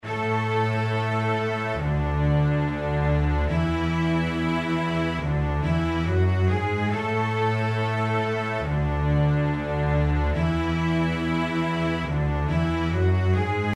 大黄蜂 弦乐
描述：弦乐来自一首正在创作中的歌曲，名为"大黄蜂quot。
标签： 140 bpm Pop Loops Strings Loops 2.31 MB wav Key : C
声道立体声